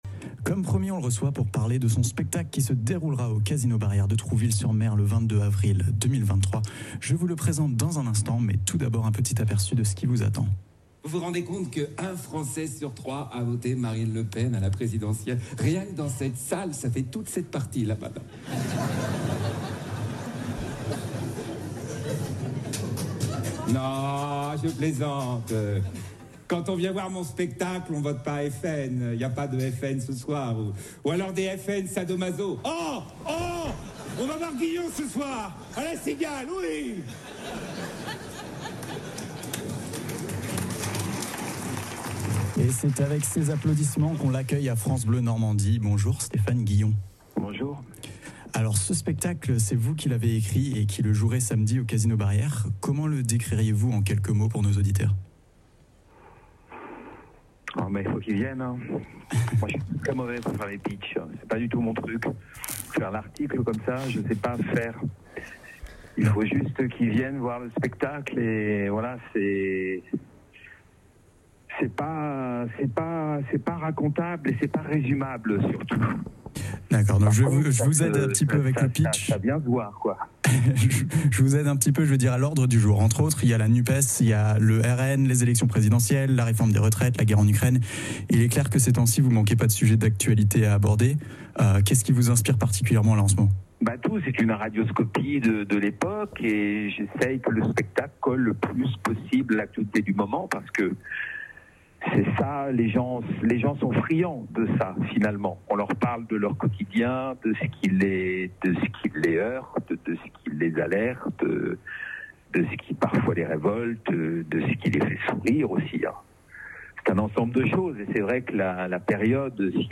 Avant sa venue samedià Trouville-sur-Planches Stéphane Guillon était hier en direct sur France Bleu Basse-Normandie.